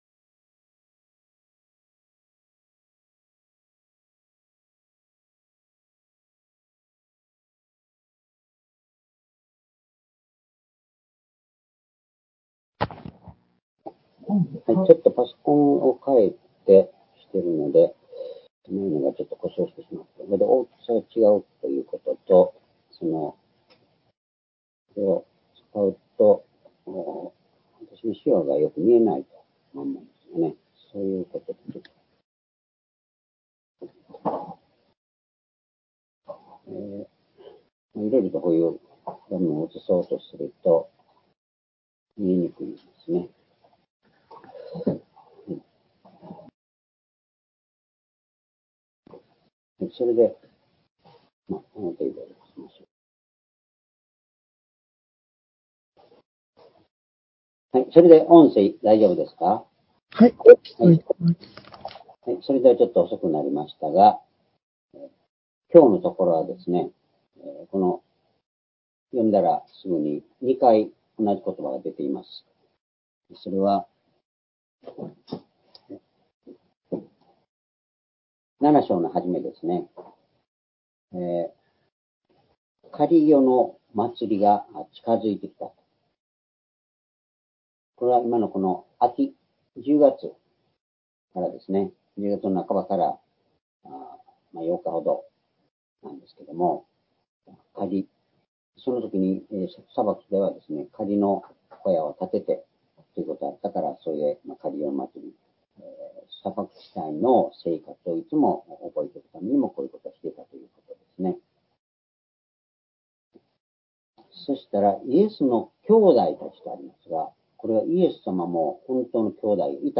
「私たちにとっての時」ヨハネ７章１節～９節―２０２２年１１月６日（主日礼拝）